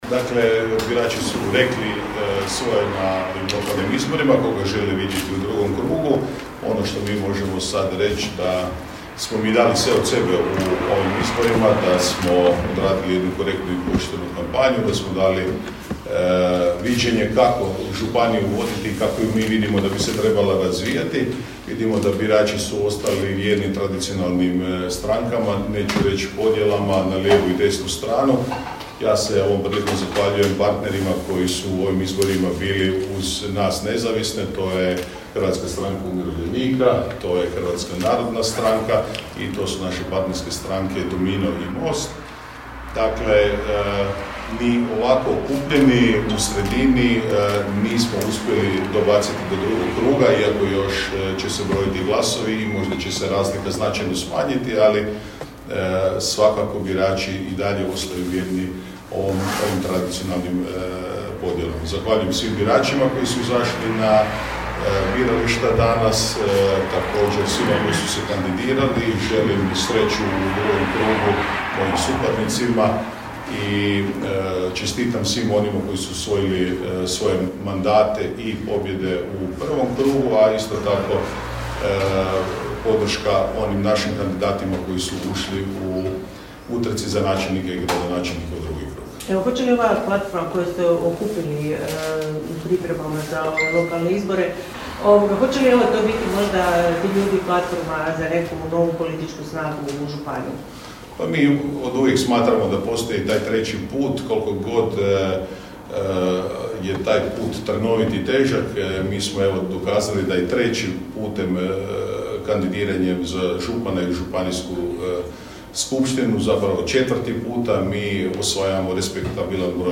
U stožeru nezavisnog kandidata za župana Koprivničko-križevačke županije Željka Lackovića dočekali su prve privremene rezultate izbora.
Evo što je Željko Lacković rekao o prvim dojmovima;